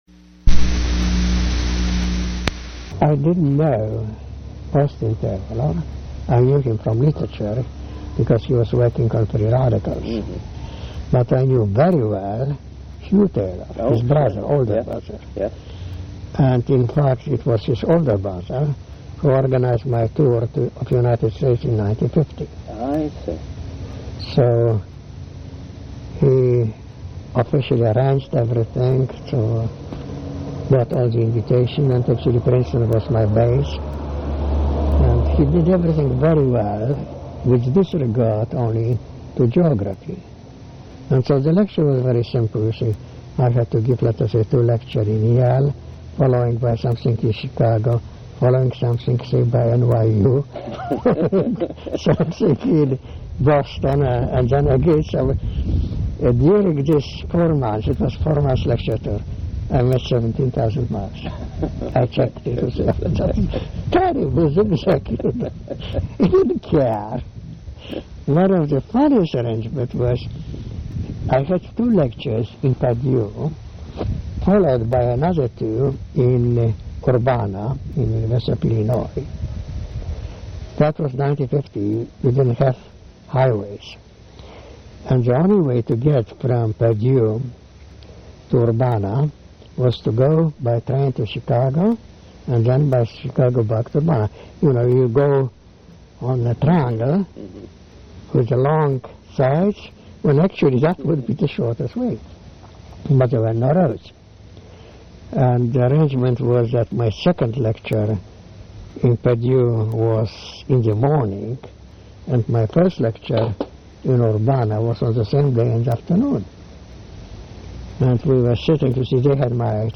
Oral history interview with Michael Szwarc